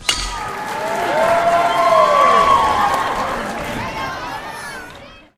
Updated horseshoe score sounds.
ringer.ogg